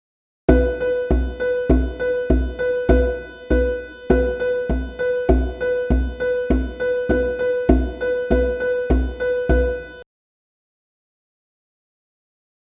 Think of beat-level syncopation as a shift in the beat; the beat still remains the same, but the shift creates emphasis on the “and”s of the count:
Example of beat level syncopation
This is also sometimes called off-beat syncopation.
6-Beat-Level.mp3